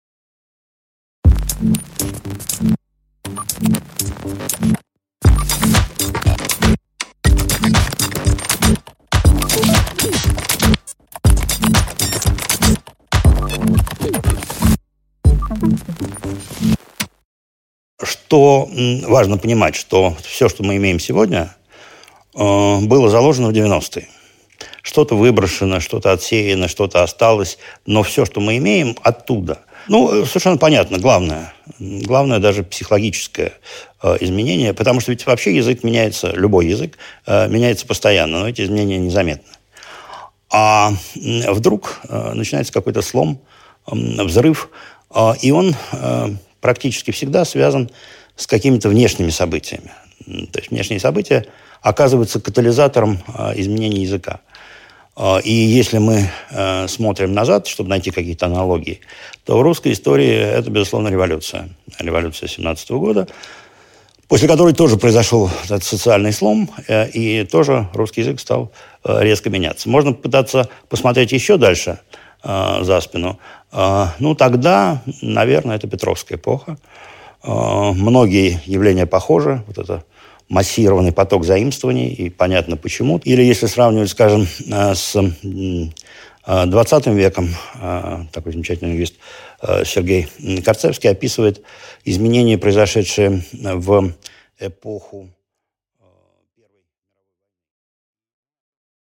Аудиокнига Как изменилась наша речь и наше общение | Библиотека аудиокниг